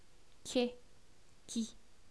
Consonnes - Sujet #1
che chi
che_chi1_[24b].wav